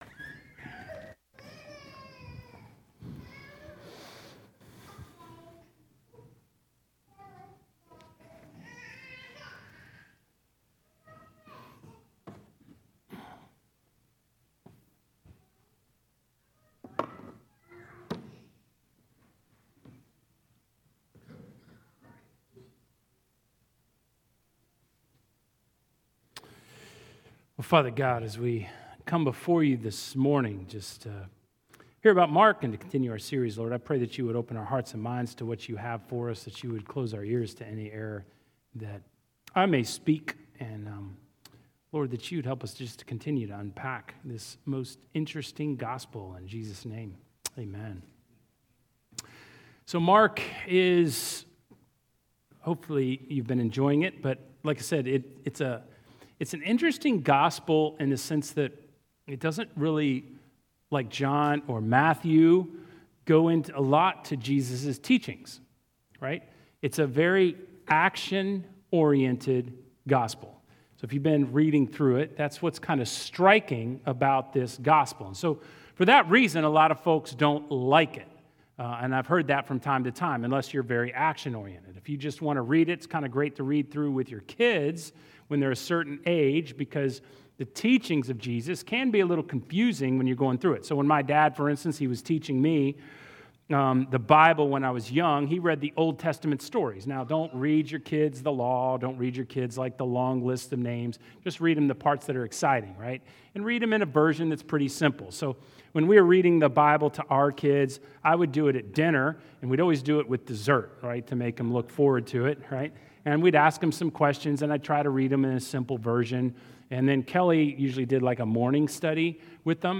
Sermons by St. Andrews Anglican Church– Ouça o Fourth Sunday of Epiphany - HC [February 02, 2025] (Year 1 C) / Broadcast de St. Andrews Anglican Church instantaneamente no seu tablet, telefone ou navegador - sem fazer qualquer download.